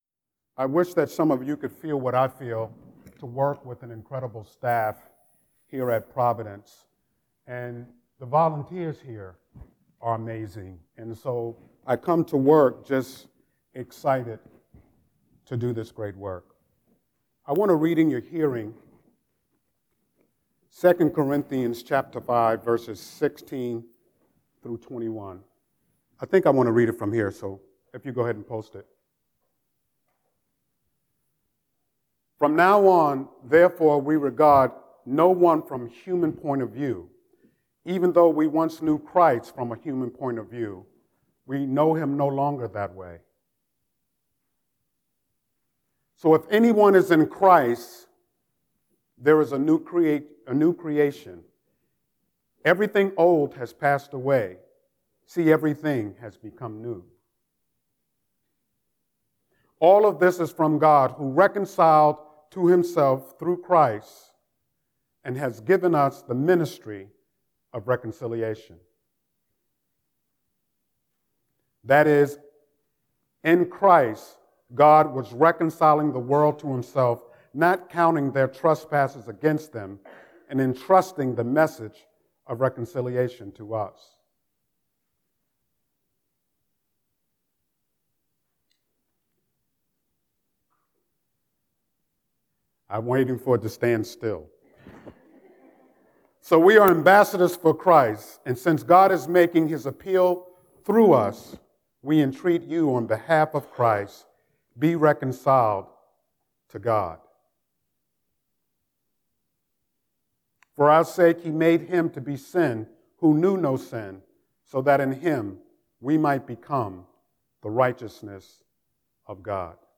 03-06-Scripture-and-Sermon.mp3